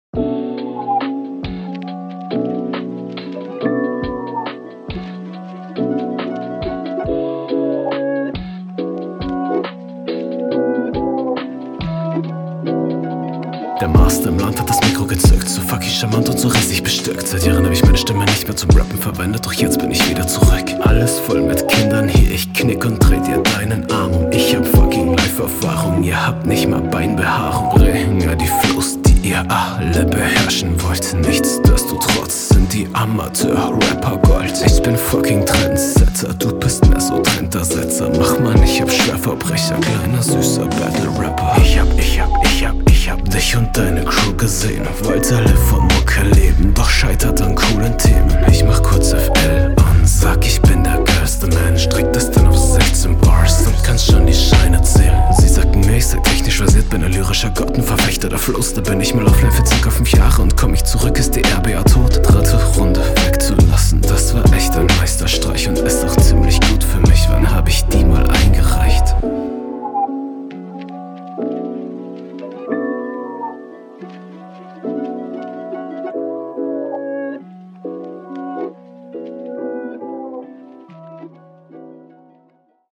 Stimme hart im Arsch Wenn ich kein Blut spucke ist es ein geiler Tag.